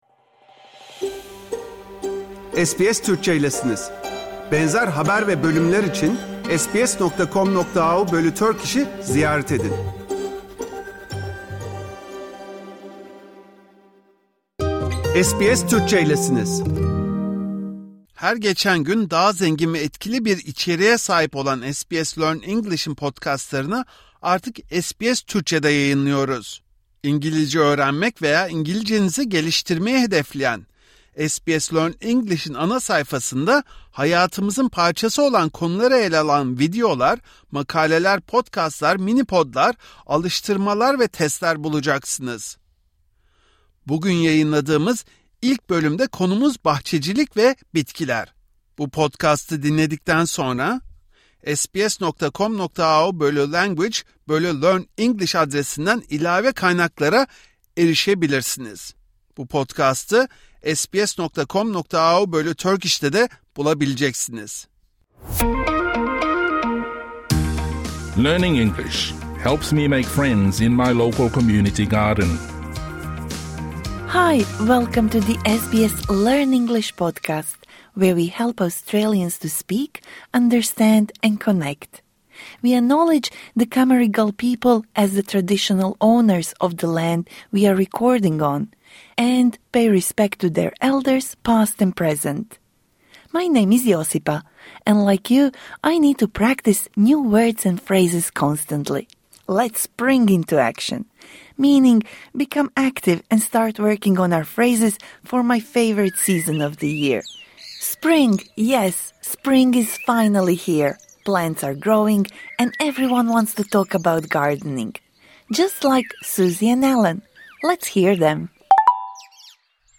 Bu İngilizce dersi orta üstten ileri seviyeye kadar olan kişiler için.